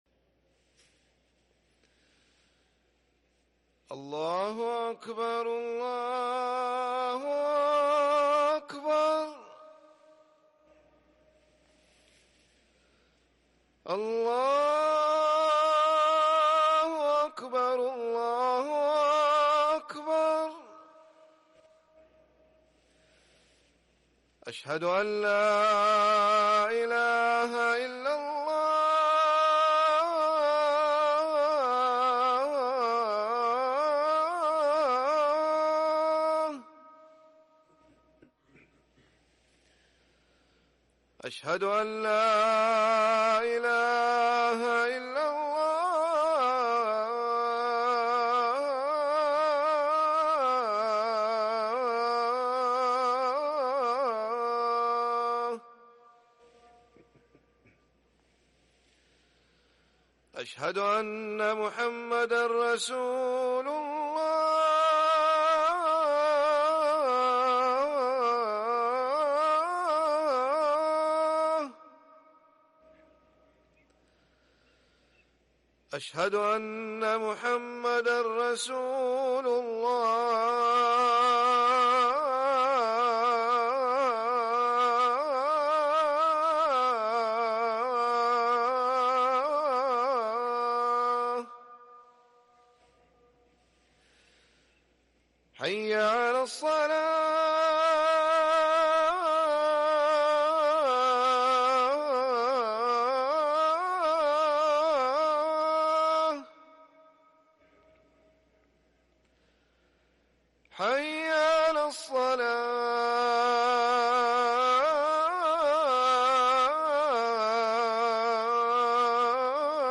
اذان الظهر